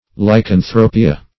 Lycanthropia \Ly`can*thro"pi*a\, n.
lycanthropia.mp3